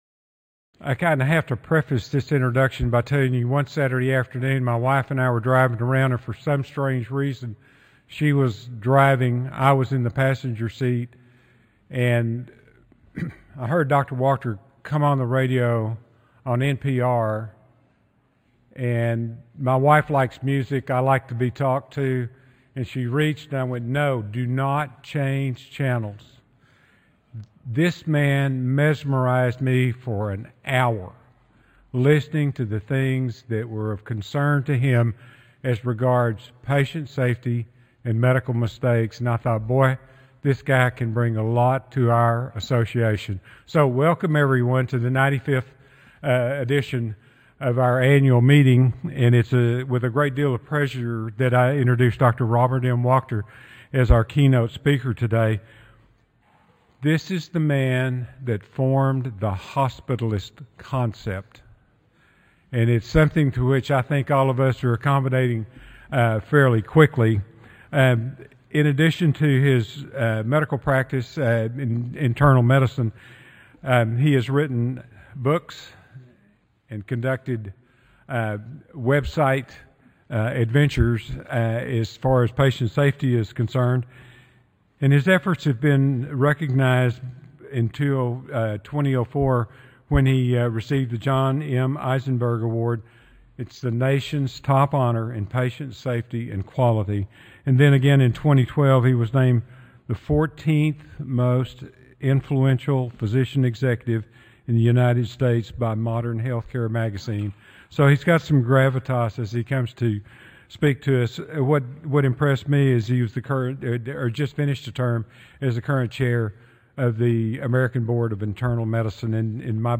G01 Keynote Lecture Wednesday, October 9, 2013 1:00 PM-2:00 PM Location Valencia Ballroom W415A (Orange County Convention Center) This is a non-ticketed session.